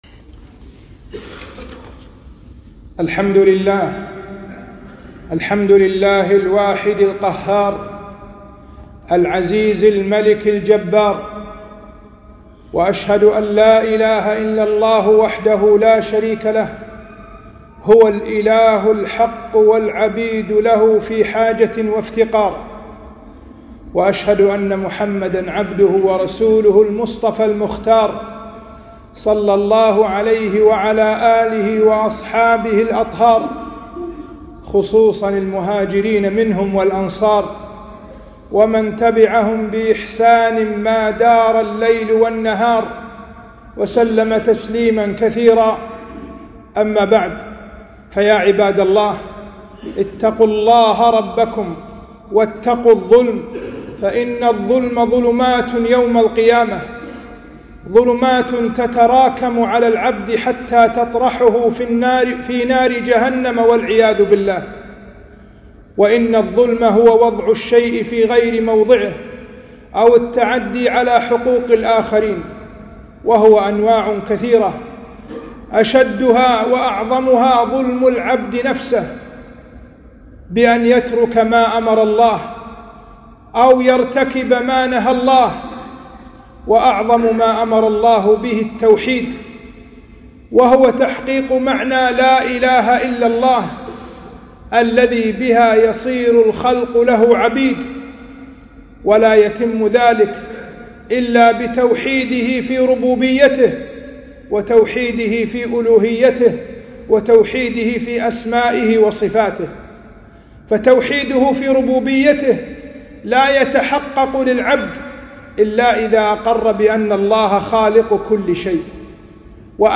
خطبة - أعظم الظلم